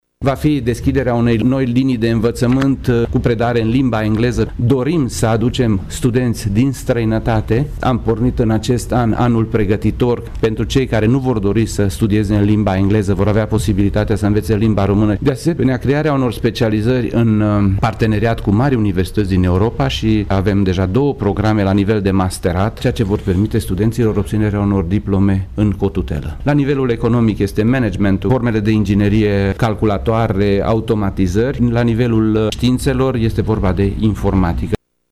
Evenimentul a avut loc la Sala Polivalentă din Tîrgu-Mureș, unde cei 1212 absolvenți la licență, masterat și doctorat au fost sărbătoriți de conducerea univesității, rude și prieteni.